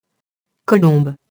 colombe [kɔlɔ̃b] nom féminin (lat. columba, pigeon)